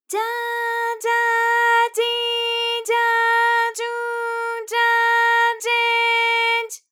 ALYS-DB-001-JPN - First Japanese UTAU vocal library of ALYS.
ja_ja_ji_ja_ju_ja_je_j.wav